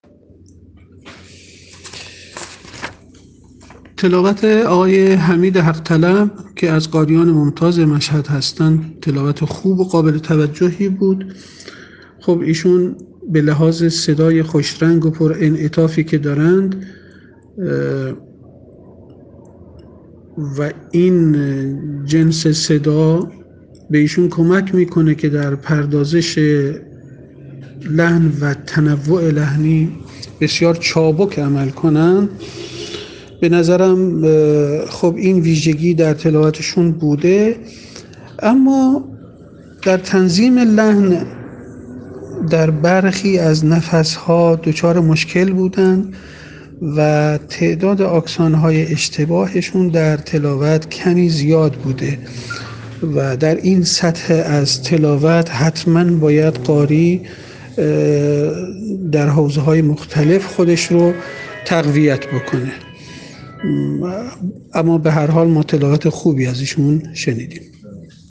فیلم اجرای قاریان فینالیست در اولین شب مسابقات سراسری قرآن
ایشان به خاطر صدای خوش‌رنگ و پر انعطافی که دارند، می‌توانند در پردازش لحن و تنوع لحنی بسیار چابک عمل کنند. این ویژگی در تلاوت ایشان وجود داشت اما در تنظیم لحن در برخی نفس‌ها دچار مشکل بودند و تعداد آکسان‌های اشتباه در تلاوت کمی زیاد بود.